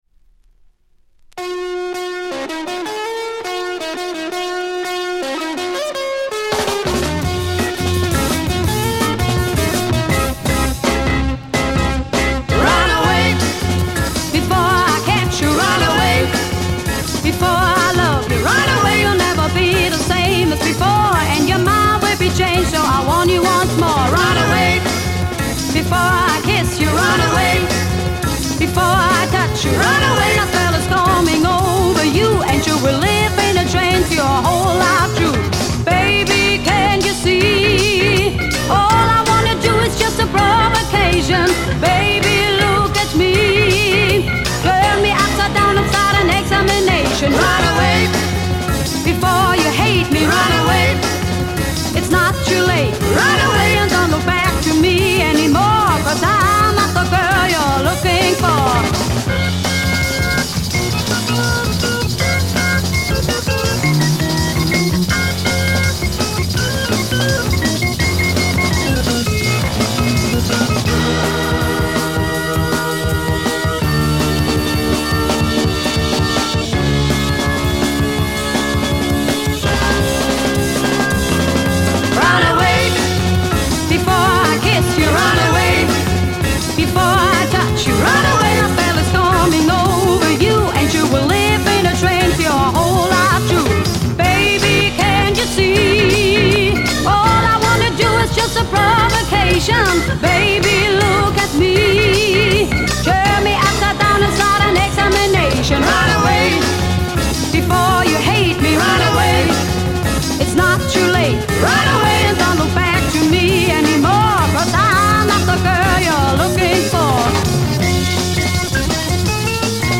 German Female Freakbeat Mod